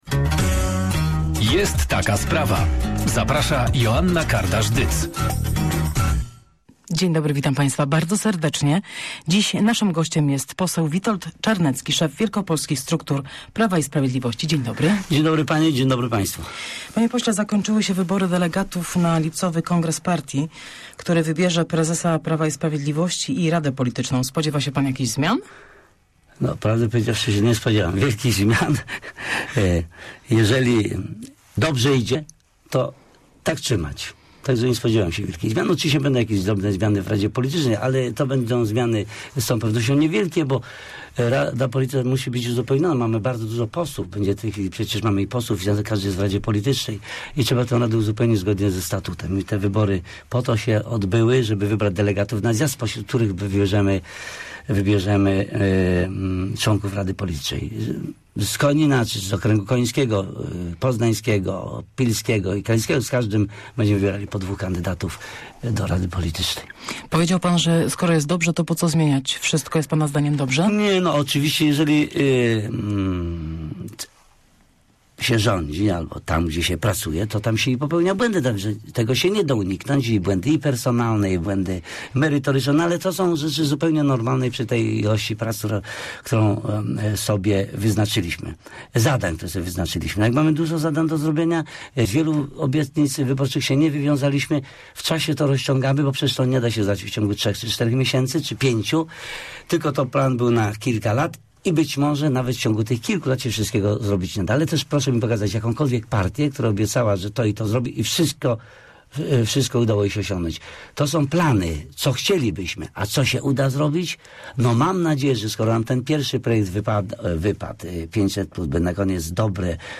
Obniżenie wieku emerytalnego uda nam się wprowadzić jeszcze w tej kadencji, natomiast nie wszystkie obietnice przedwyborcze zostaną zrealizowane w ciągu tych czterech lat, bo do wszystkiego potrzebny jest czas - powiedział w porannej rozmowie Radia Merkury szef wielkopolskich struktur Prawa i Sprawiedliwości poseł Witold Czarnecki.